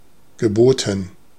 Ääntäminen
Ääntäminen Tuntematon aksentti: IPA: /ɡe.ˈbo.tən/ Haettu sana löytyi näillä lähdekielillä: saksa Käännöksiä ei löytynyt valitulle kohdekielelle. Geboten on sanan bieten partisiipin perfekti.